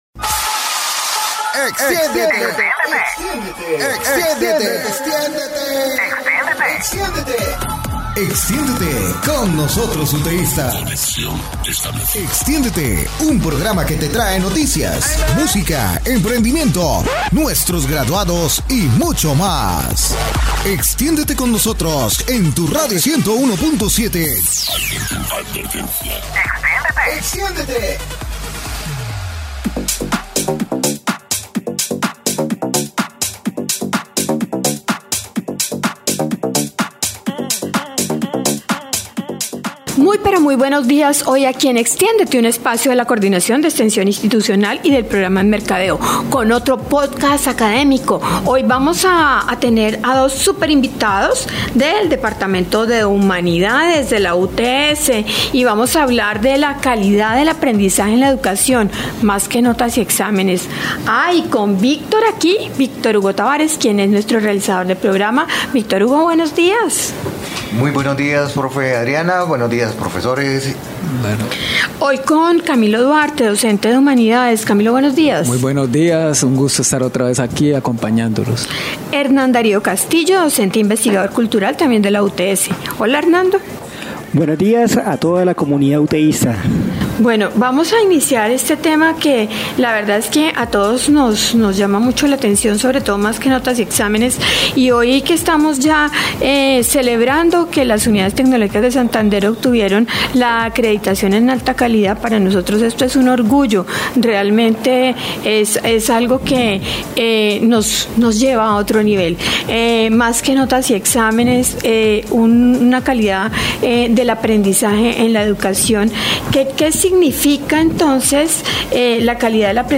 A través de diálogos profundos con expertos, se exploran temas cruciales como el impacto de las evaluaciones tradicionales en el aprendizaje, la imperativa necesidad de garantizar la equidad en el acceso a la educación y la búsqueda de estrategias innovadoras para fortalecer la formación docente.